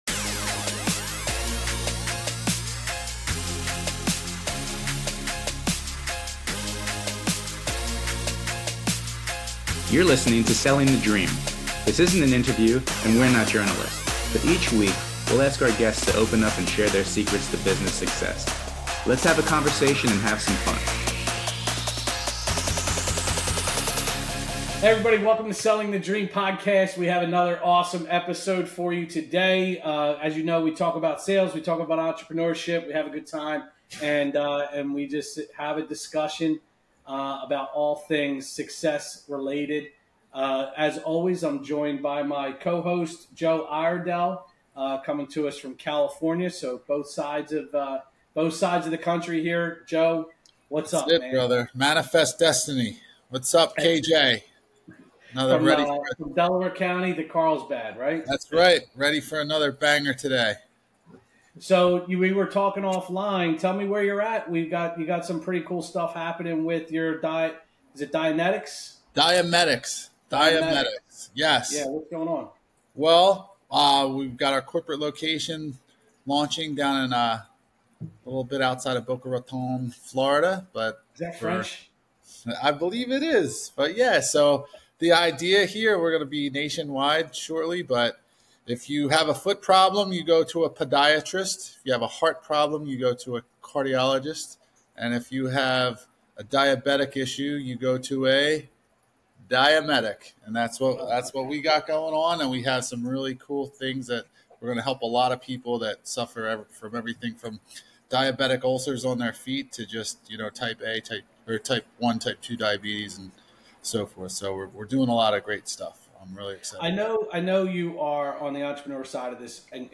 Talking